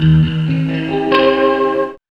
2604L GTRJAZ.wav